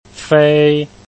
Pronuncia Aiuto
fei1 zhou1